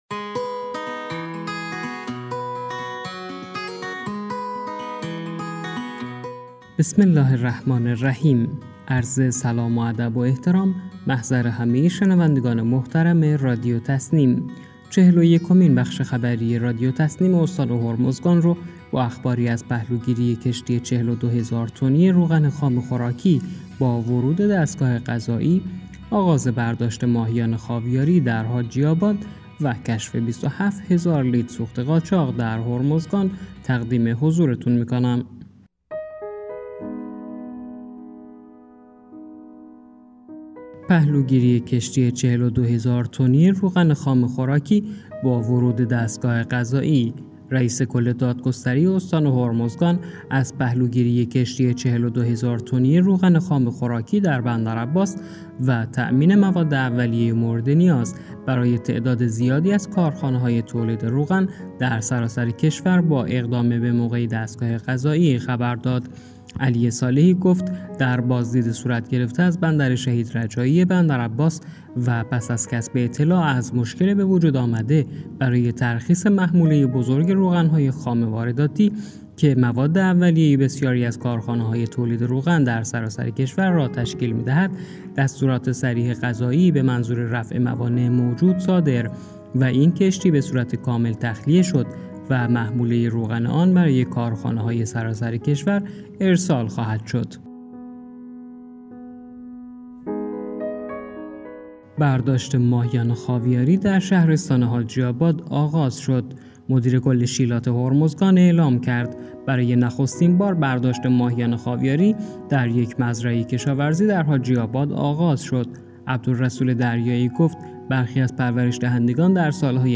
گروه استان‌ها- چهلمین و یکمین بخش خبری رادیو تسنیم استان هرمزگان با بررسی مهم‌ترین اخبار این استان در 24 ساعت گذشته منتشر شد.